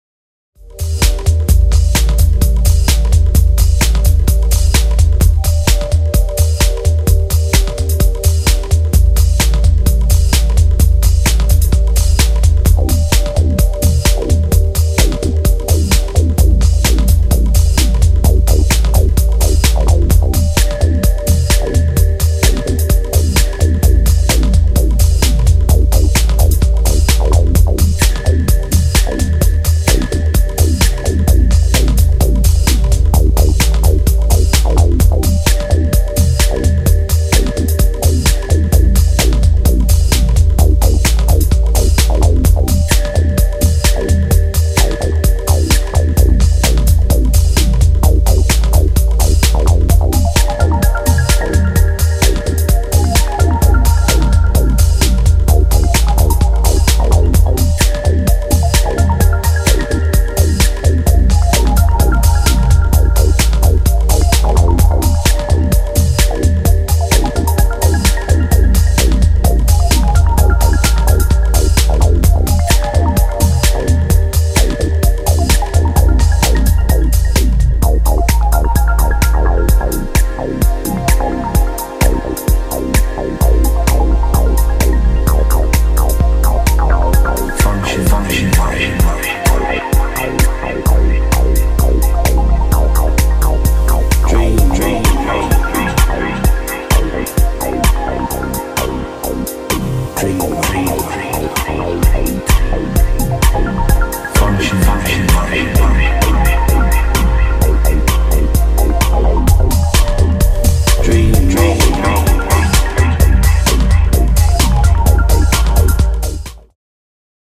角が取れつつ、しっかりとフロアを主導するビートが心地良い3トラックス、流石でございます！